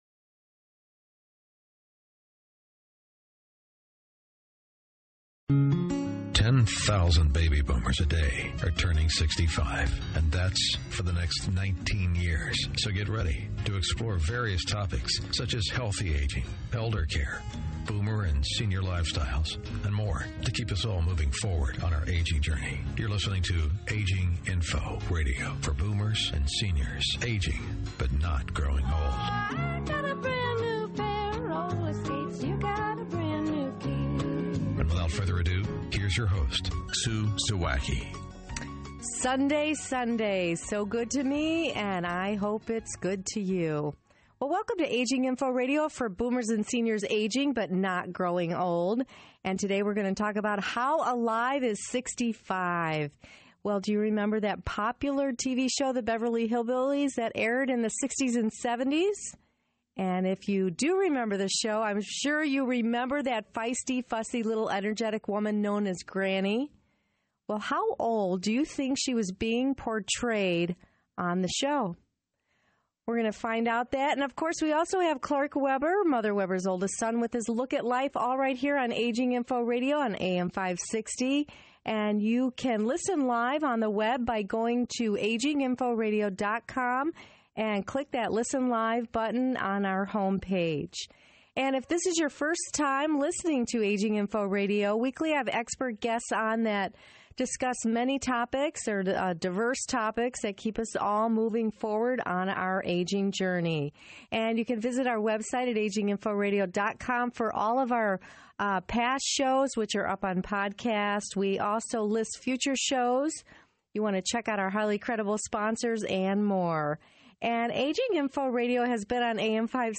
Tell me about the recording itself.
This podcast is taken from a radio show I was a guest on in Chicago.